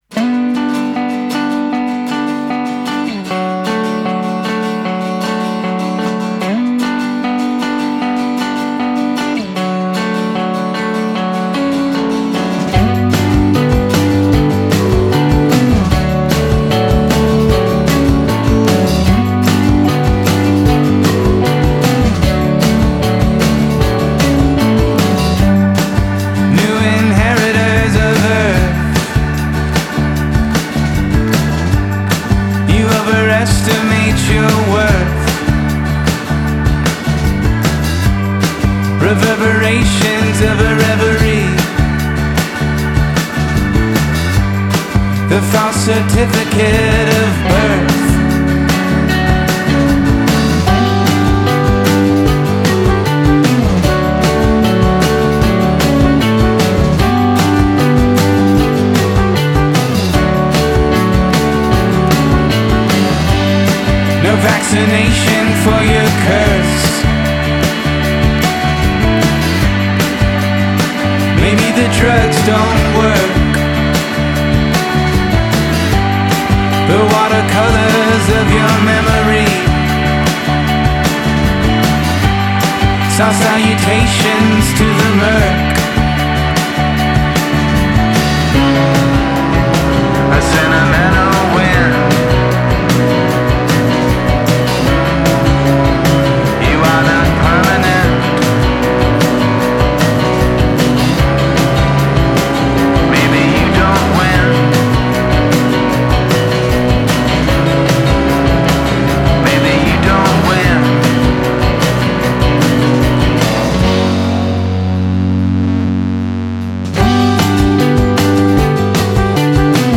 guitarist
sings with an easygoing spirit